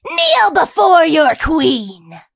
willow_lead_vo_06.ogg